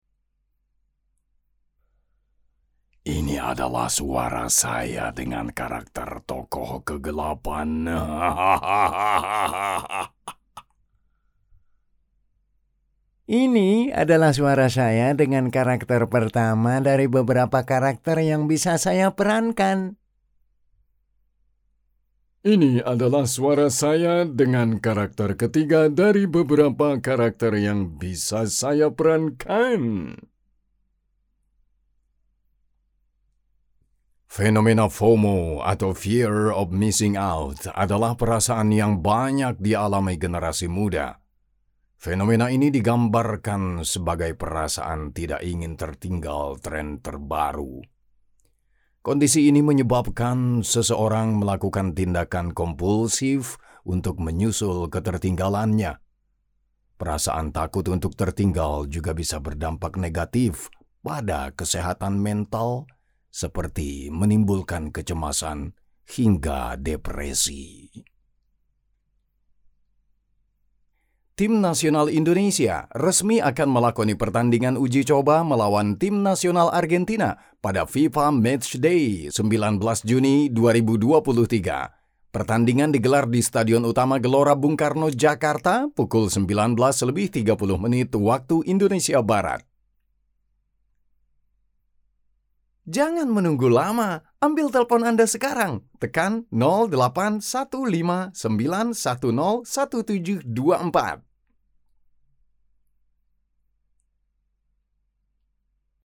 Commercial, Deep, Natural, Versatile, Corporate
Many people say that his voice is distinctive, strongly masculine, and pleasant to listen to.